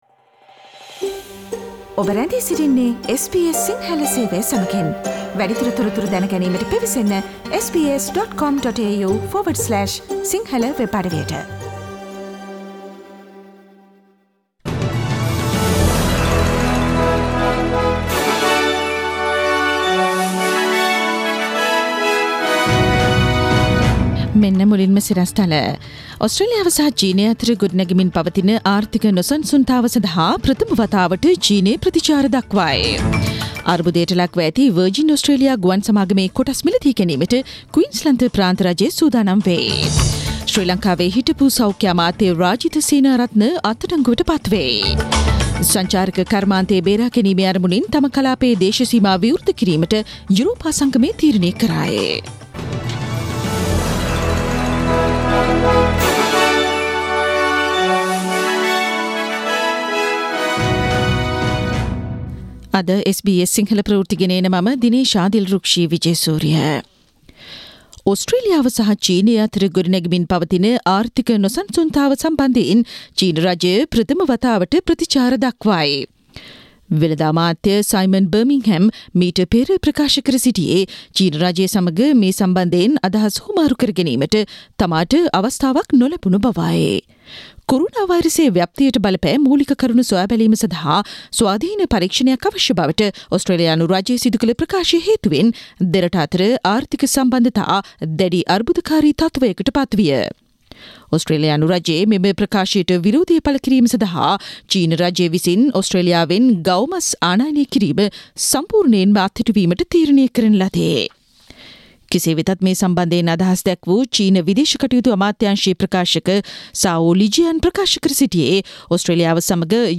Daily News bulletin of SBS Sinhala Service: Thursday 14 May 2020
Today’s news bulletin of SBS Sinhala radio – Thursday 14 May 2020.